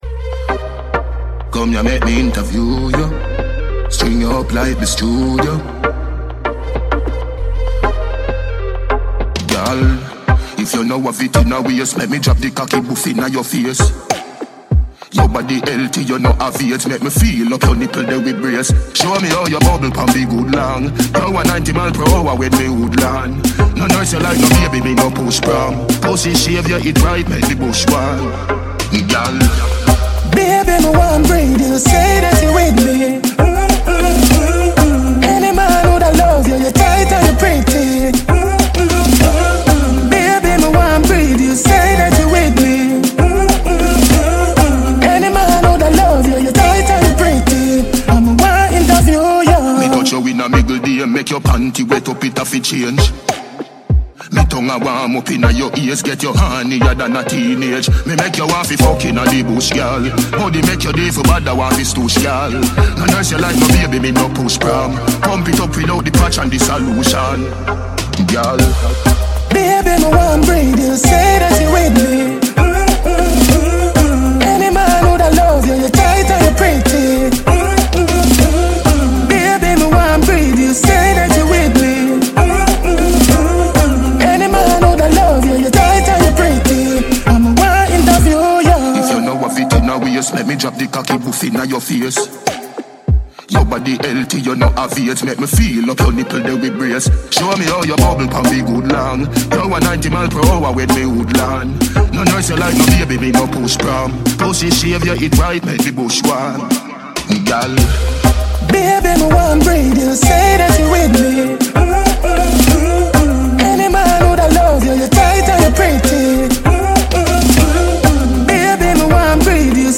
Dancehall/HiphopMusic
Jamaican Dancehall act